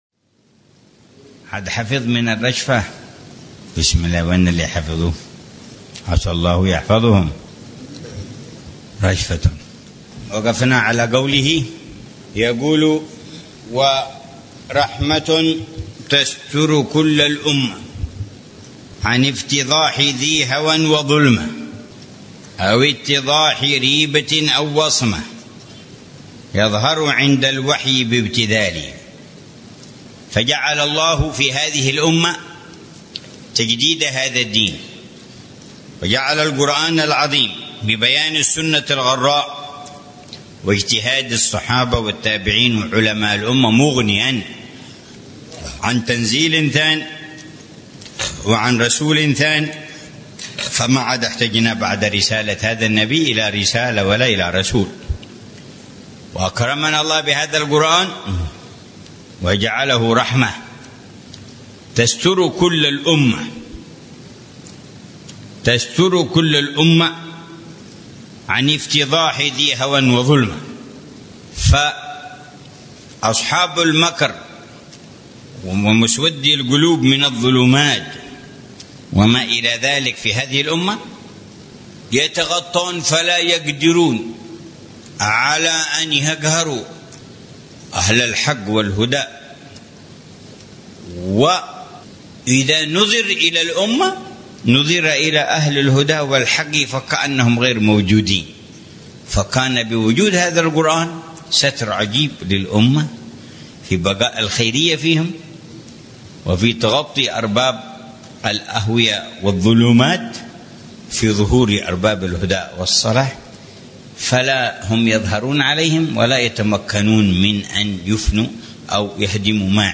شرح الحبيب عمر بن محمد بن حفيظ لرشفات أهل الكمال ونسمات أهل الوصال.